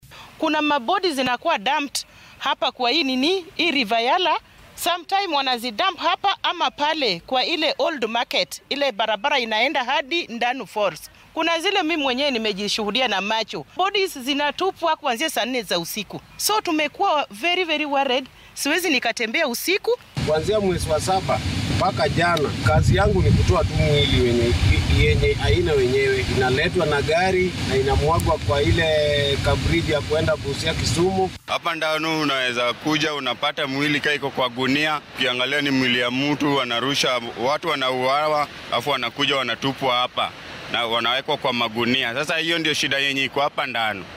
Qaar ka mid ah dadka deegaankaasi ayaa dhacdadan uga warramay warbaahinta